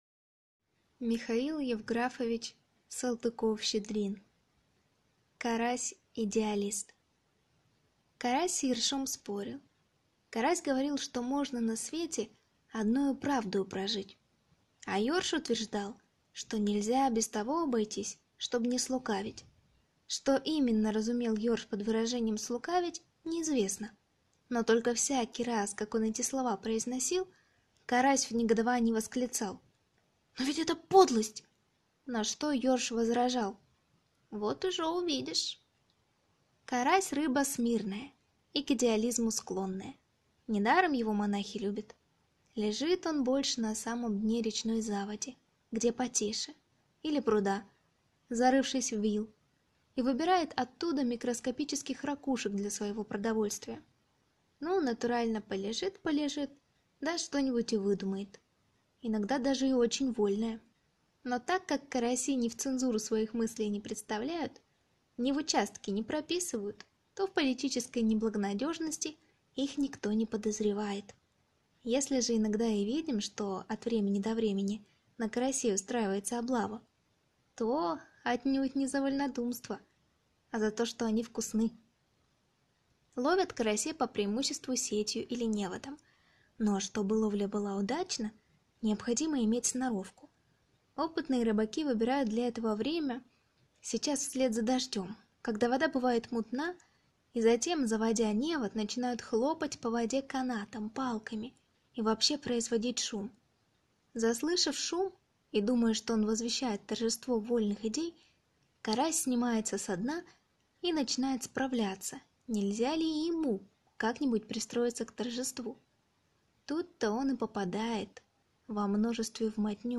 Аудиокнига Карась-идеалист | Библиотека аудиокниг